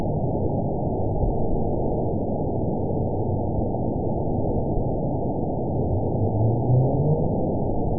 event 914152 date 04/29/22 time 05:29:41 GMT (3 years ago) score 8.76 location TSS-AB01 detected by nrw target species NRW annotations +NRW Spectrogram: Frequency (kHz) vs. Time (s) audio not available .wav